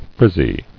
[friz·zy]